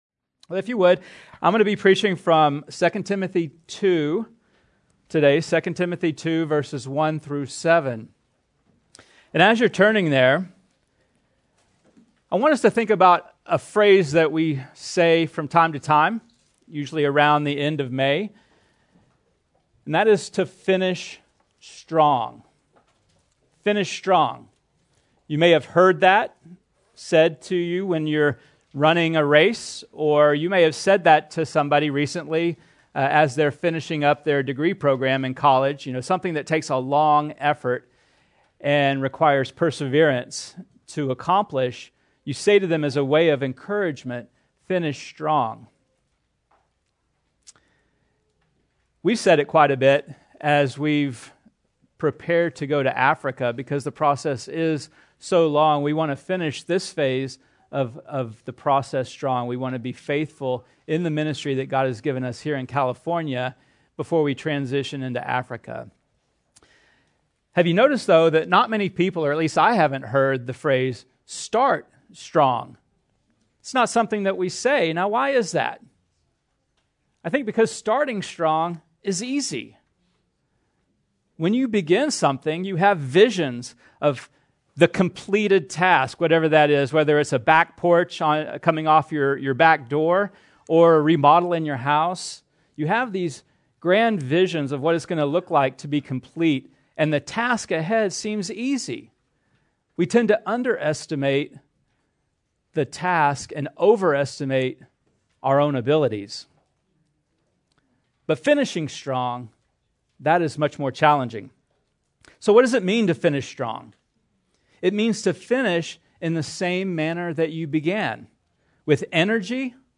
Recent sermons from Commissioned, a ministry of Grace Community Church in Sun Valley, California.